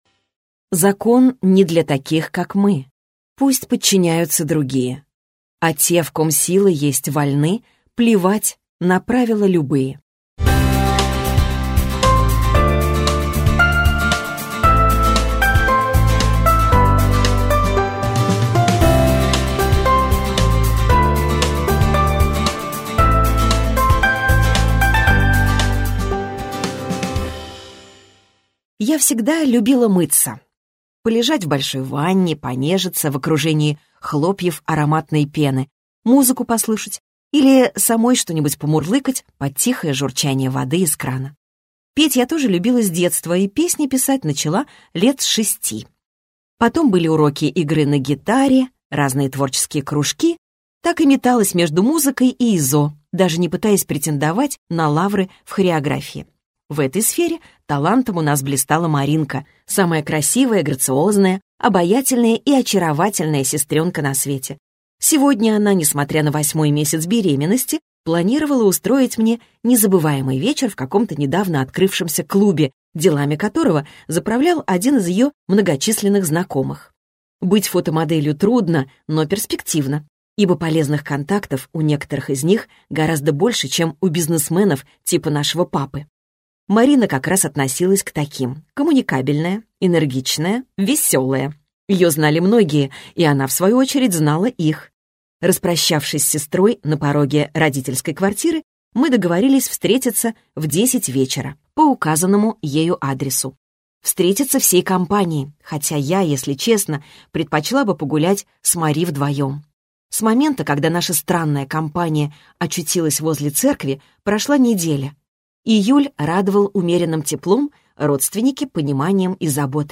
Аудиокнига Моя темная «половина» - купить, скачать и слушать онлайн | КнигоПоиск